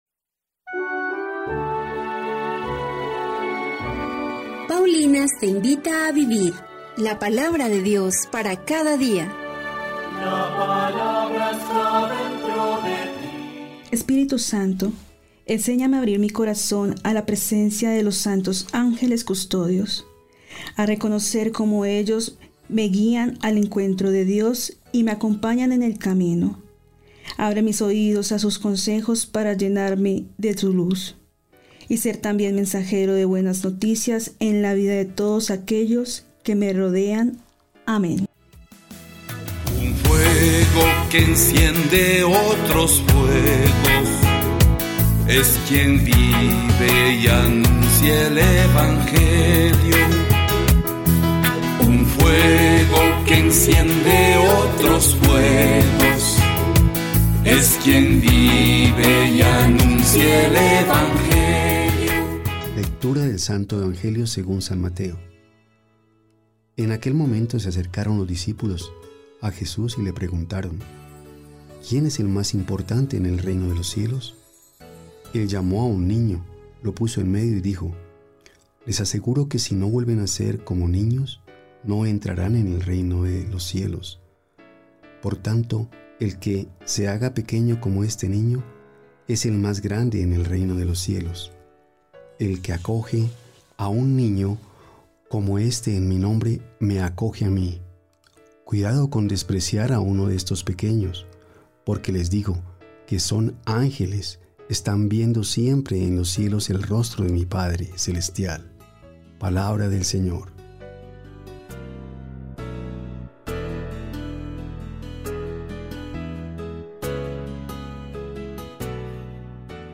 Primera Lectura
L: Palabra de Dios T: Te alabamos, Señor Salmo responsorial 90, 1-6. 10-11 R. A sus ángeles ha dado órdenes, para que te guarden en tus caminos.